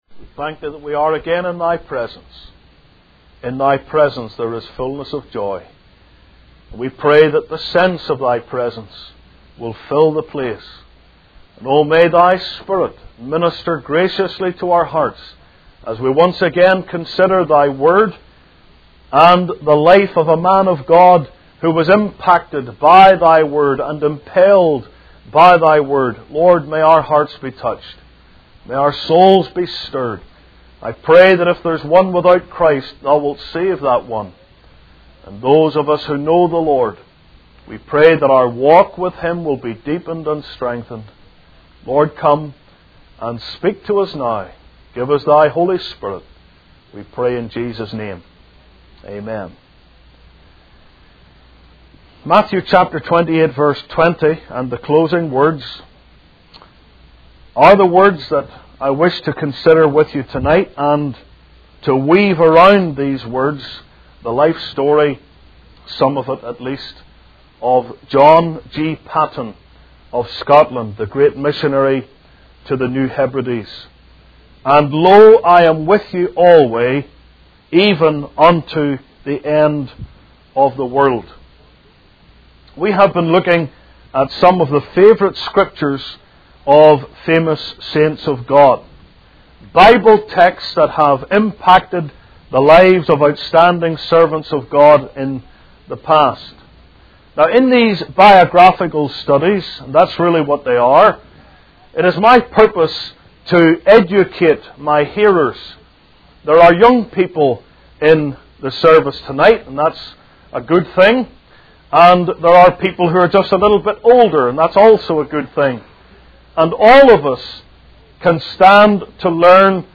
In this sermon, the preacher discusses the concept of heroes and role models in the world today. He contrasts the popular figures idolized by young people, such as movie stars and rock stars, with the true heroes of faith.